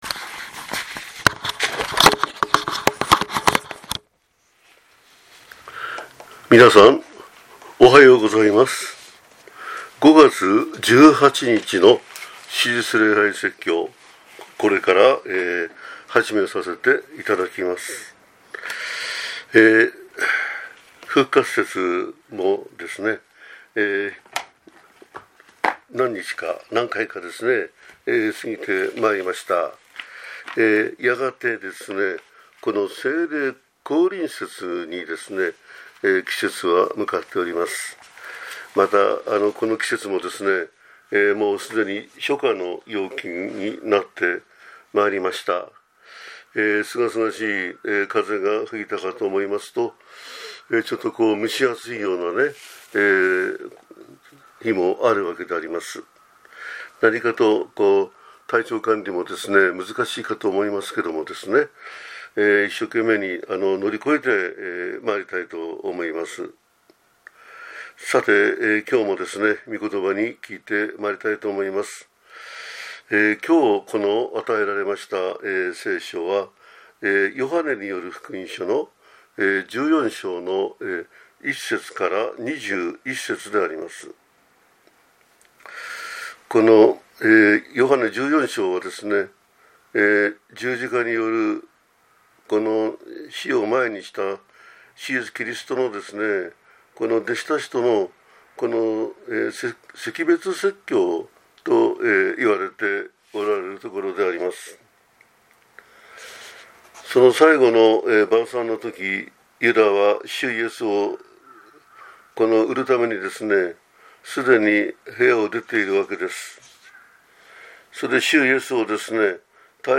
説教 神を信じ私を信じなさい